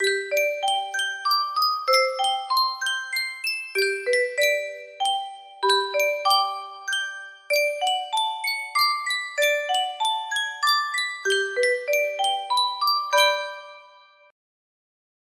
Sankyo Music Box - Moonlight on the River Colorado VCE music box melody
Full range 60